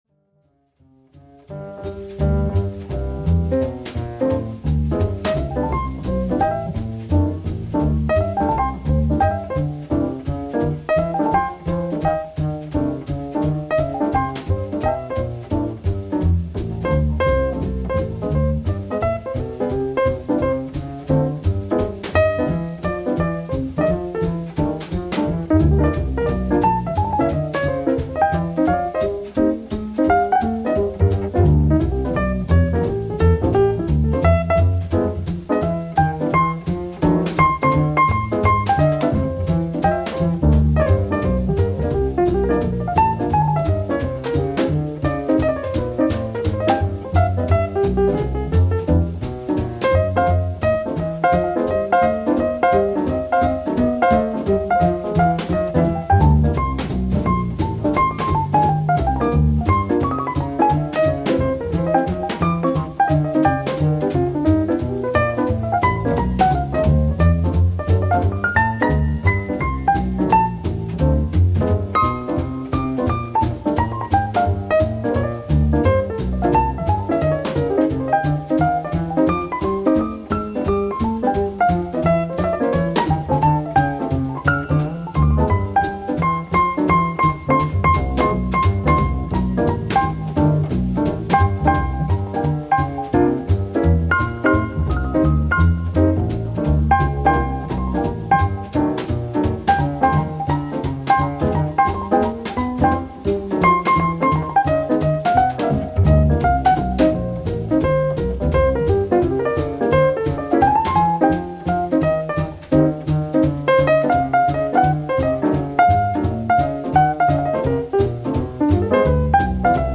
Damn, this is so swinging!